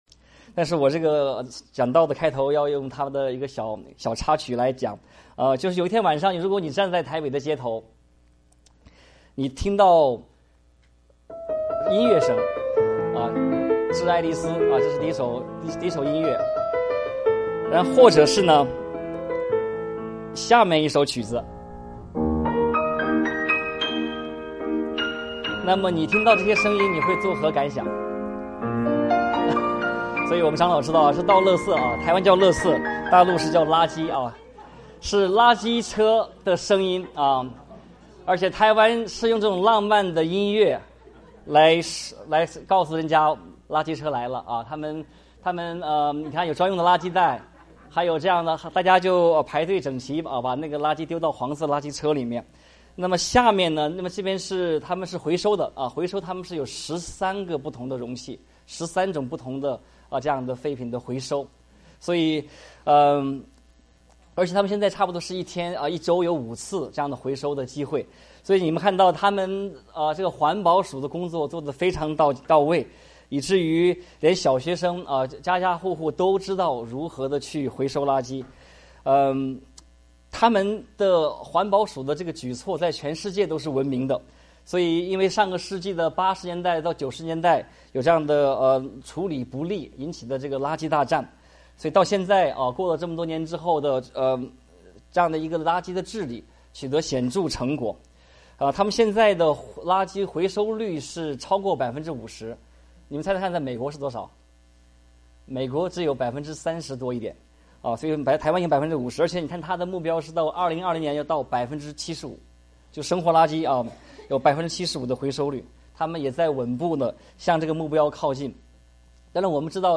傳道 應用經文: 以弗所書 4:25-5:5